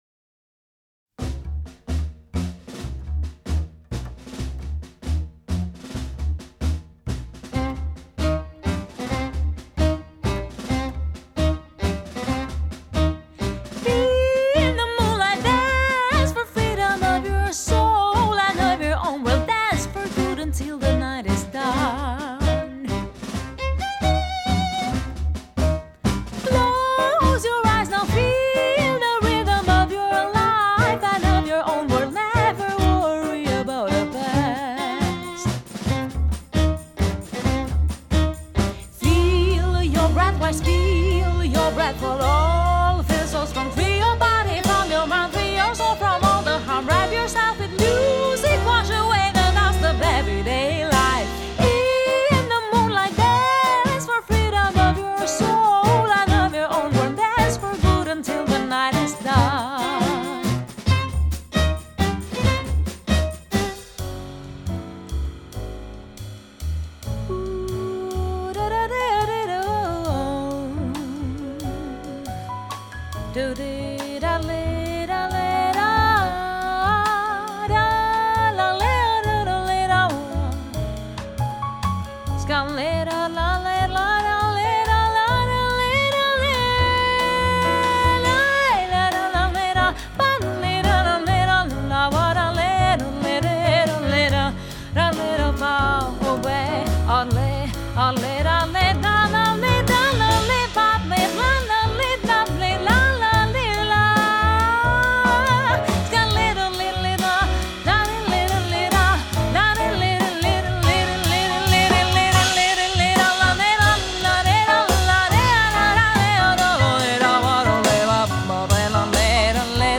Äänitetty Kallio-Kuninkalassa, Järvenpäässä.